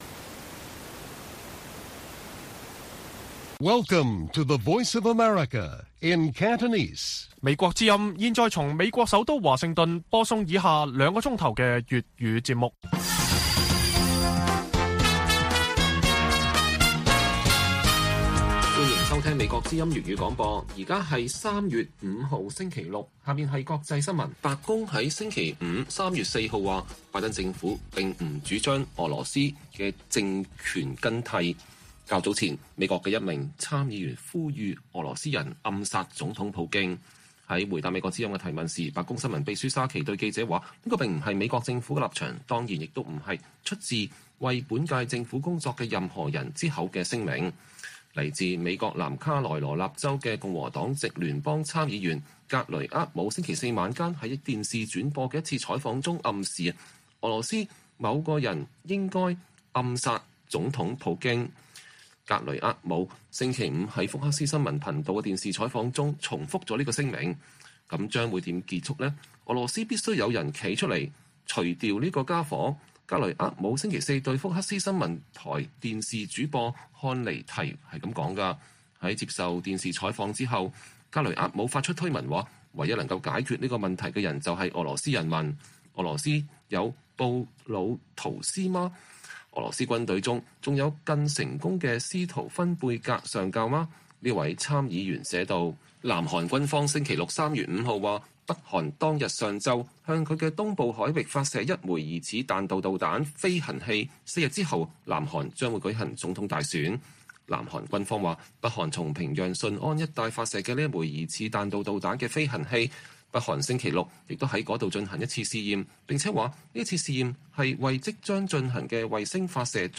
粵語新聞 晚上9-10點 : “像鷹一樣”嚴防中國趁機犯台 專家指美國對台政策漸趨清晰